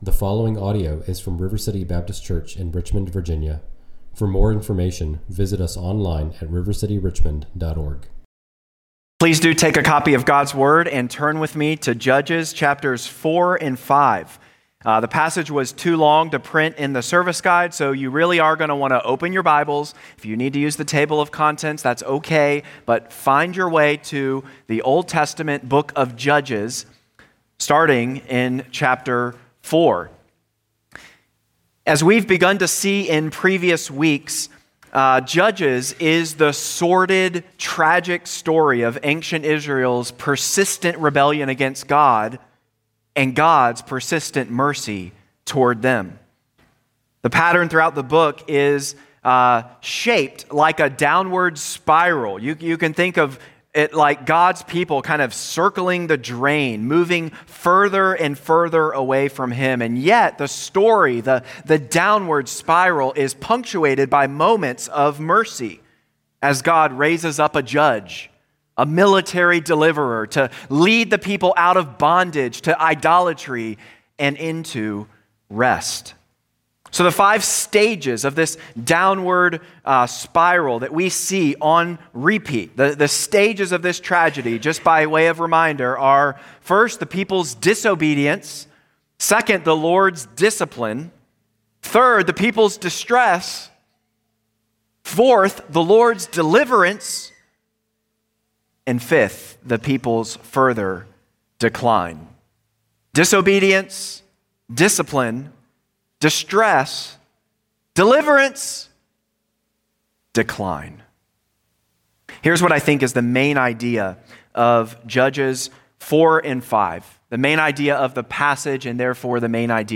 preaches Judges 4-5 at River City Baptist Church, a new congregation in Richmond, Virginia.